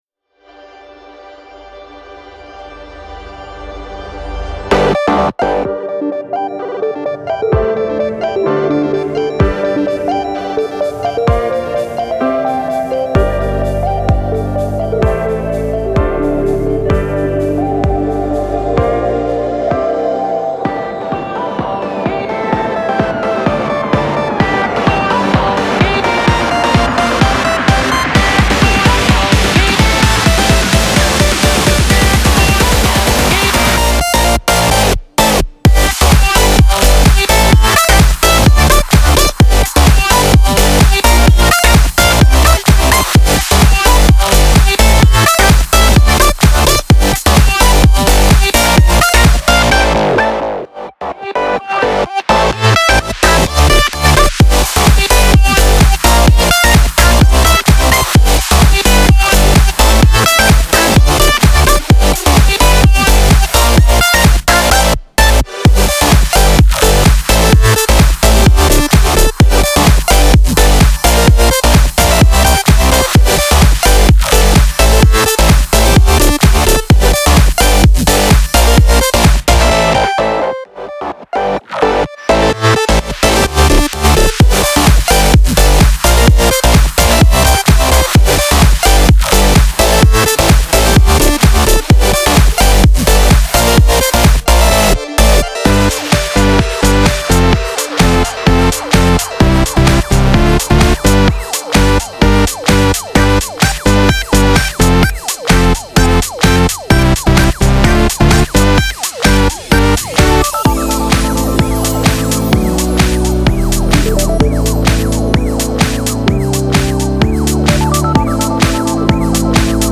BPM128-128
Audio QualityPerfect (High Quality)
Future Bass song for StepMania, ITGmania, Project Outfox
Full Length Song (not arcade length cut)